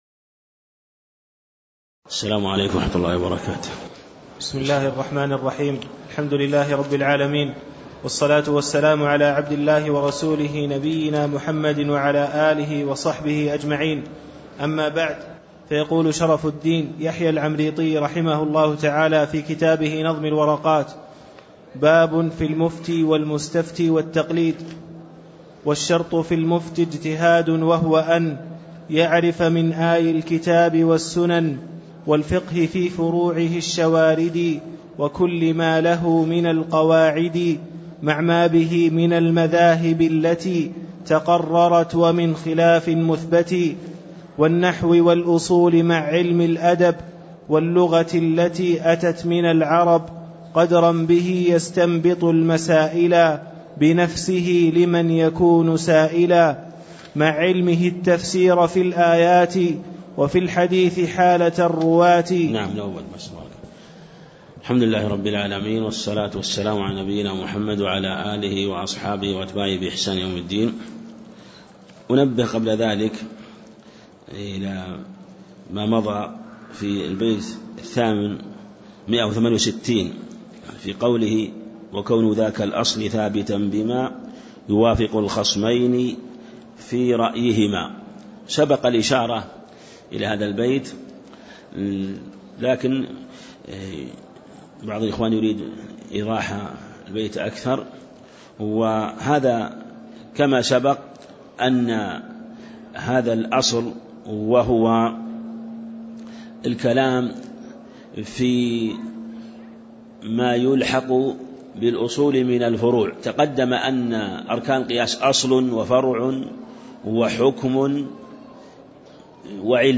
تاريخ النشر ١٨ شوال ١٤٣٦ هـ المكان: المسجد النبوي الشيخ